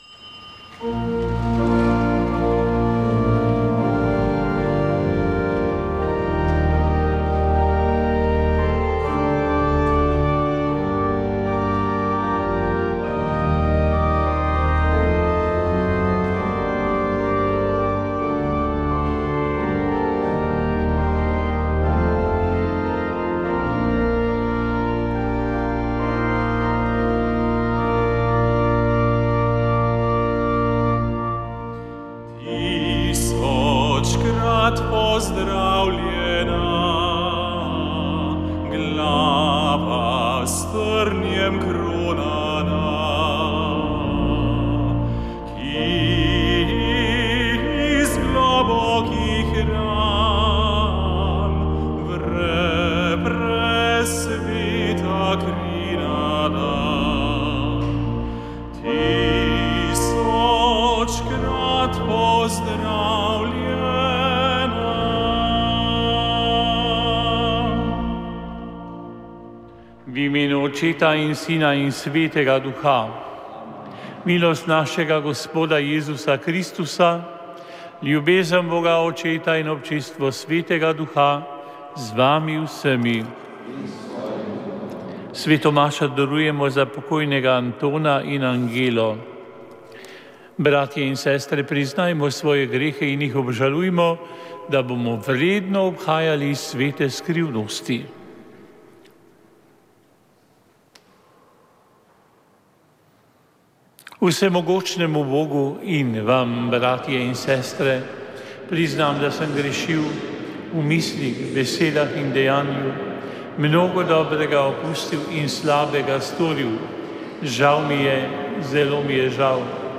Sveta maša
Sv. maša iz cerkve sv. Marka na Markovcu v Kopru 10. 1.